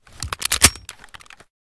vz61_unjam.ogg